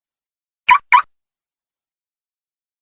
Suara Alarm Kunci Mobil
Kategori: Suara bel berbunyi
Keterangan: Suara alarm kunci mobil (bunyi buka kunci mobil, suara remote mobile, electronic car key sound effect...) kini viral di TikTok dan bisa diunduh sebagai nada dering/ notif untuk WA atau semua jenis ponsel.
suara-alarm-kunci-mobil-id-www_tiengdong_com.mp3